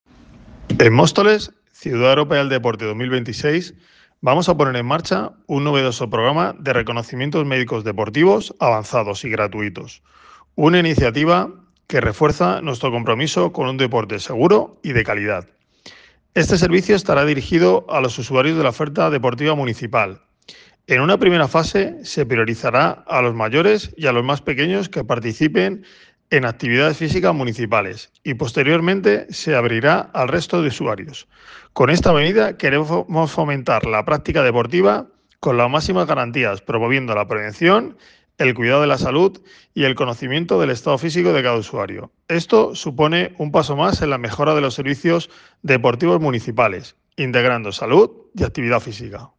Audio Ángel Álvarez (Concejal de Deportes y Sanidad) - Reconocimientos médicos deportivos